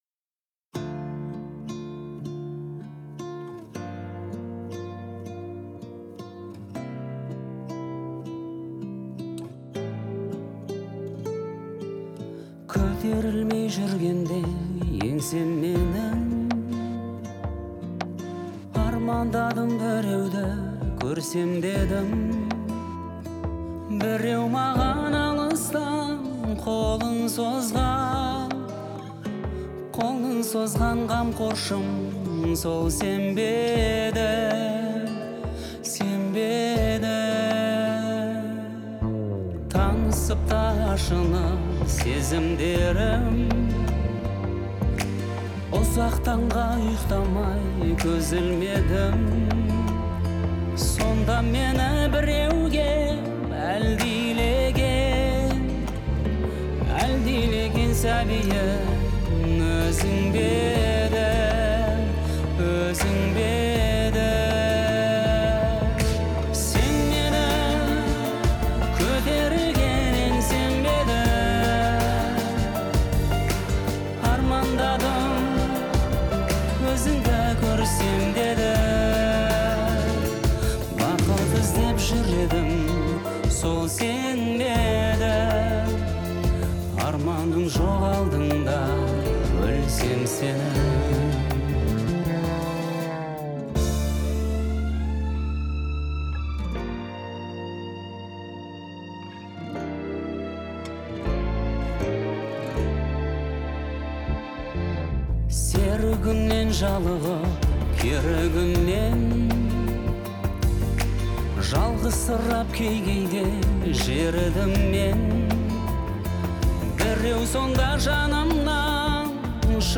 это песня в жанре казахской поп-музыки